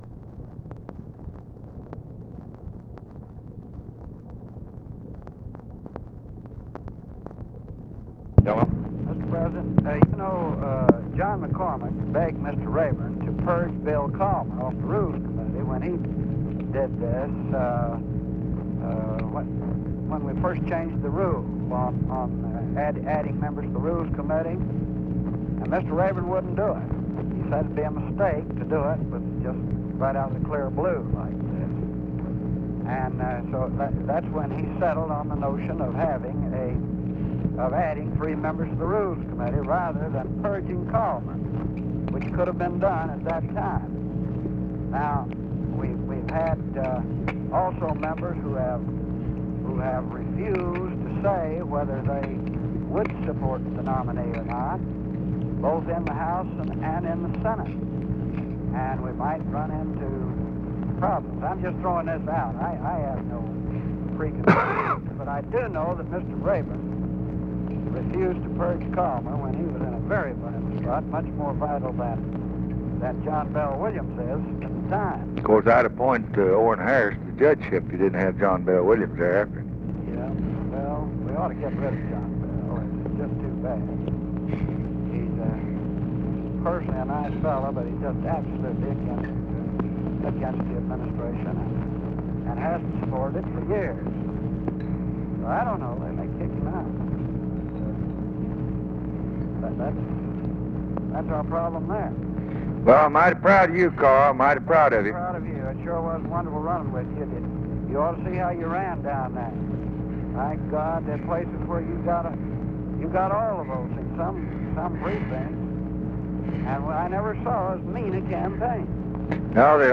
Conversation with CARL ALBERT and OFFICE CONVERSATION, November 6, 1964
Secret White House Tapes